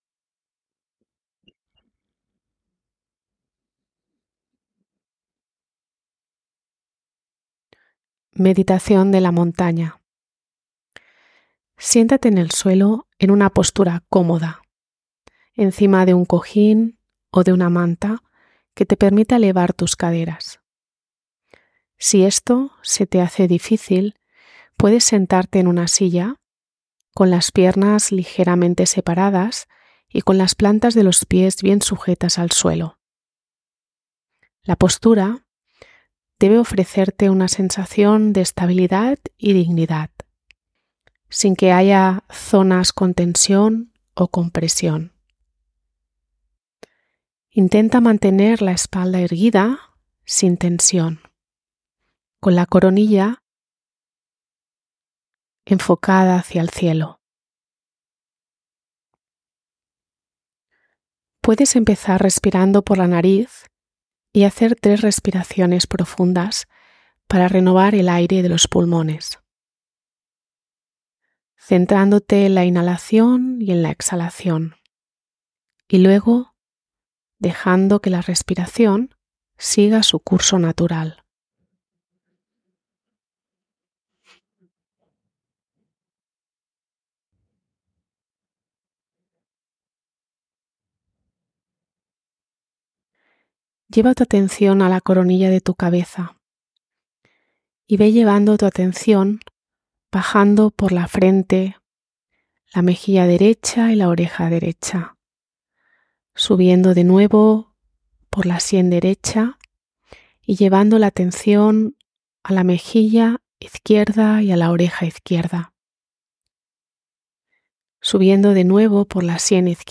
Meditacion de la montana.mp3